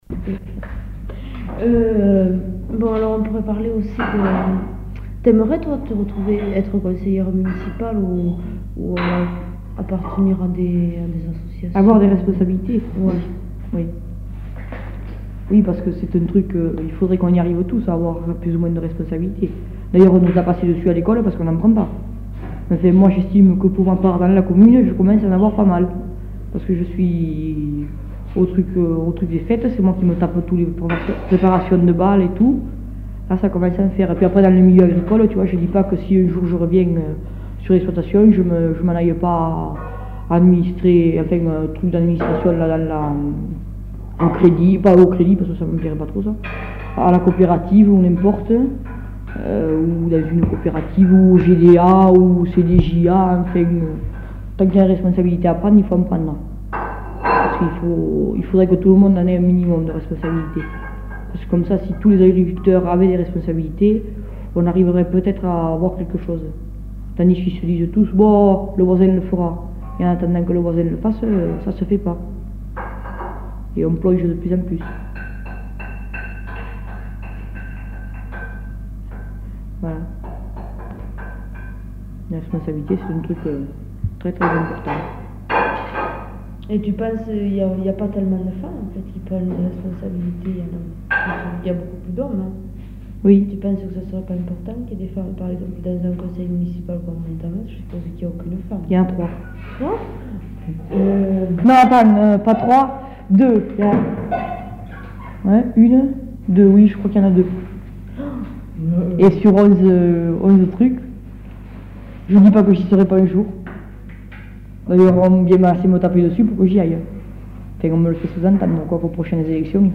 Lieu : Montamat
Genre : témoignage thématique